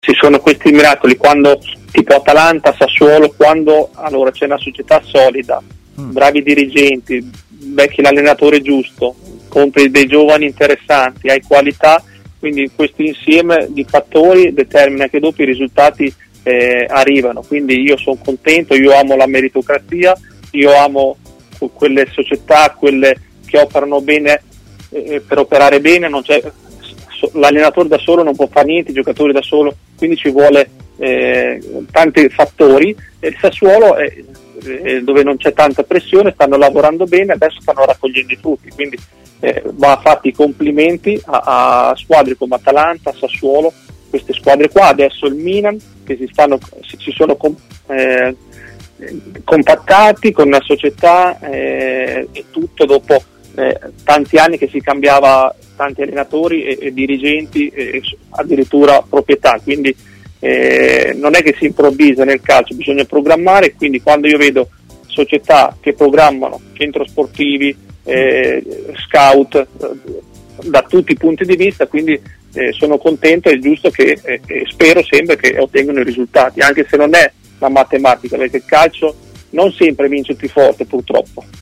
L'ex difensore di Carpi e Bologna Cristian Zaccardo è intervenuto in diretta a TMW Radio. L'ex difensore ha parlato in diretta, intervenendo in diretta a Stadio Aperto.